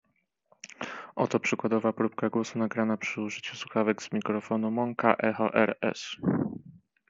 Monka ECHO RS są również wyposażone w oczepiany mikrofon, choć można spodziewać się, że jego jakość oszałamiająca nie będzie. Dźwięk generowany z mikrofonu słuchawek Monka Echo RS prezentuje się następująco: